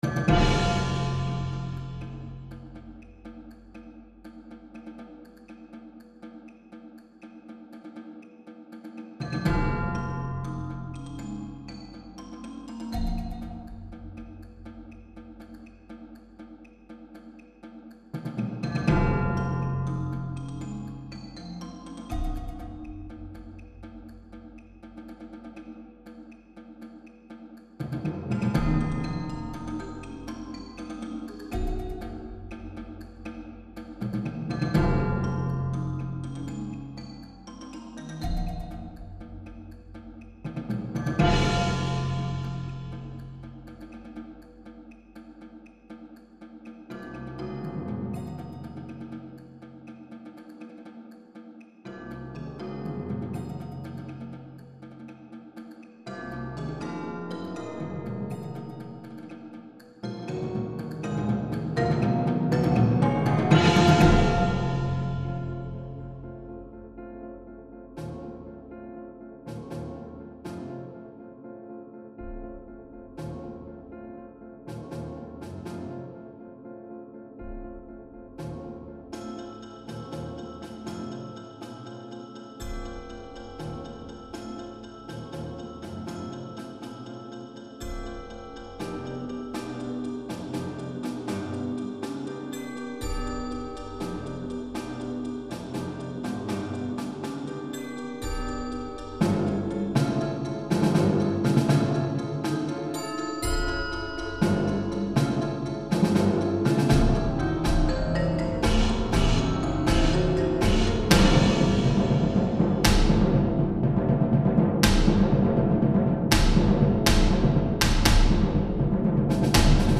Work for percussion ensemble and piano.